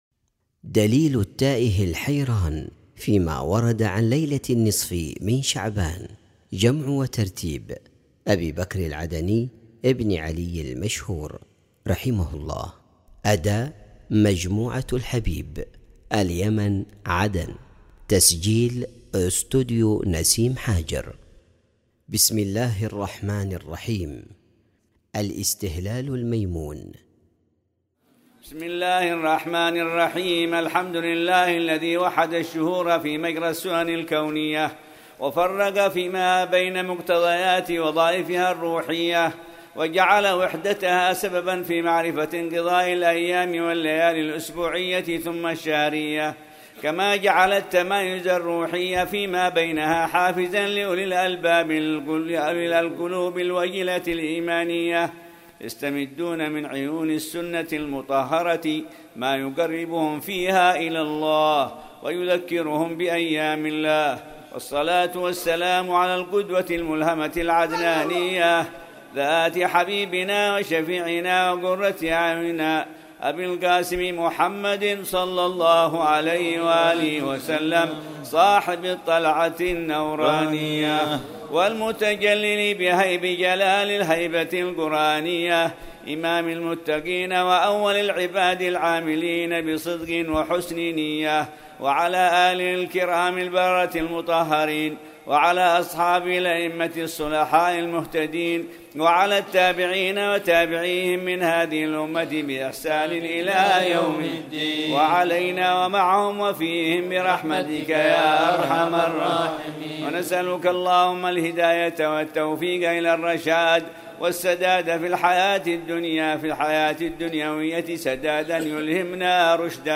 منظومات
أداء مجموعة الحبيب